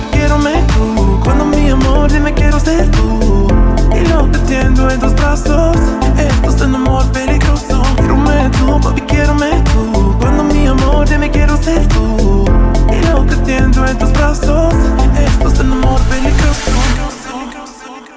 Category: Spanish Ringtones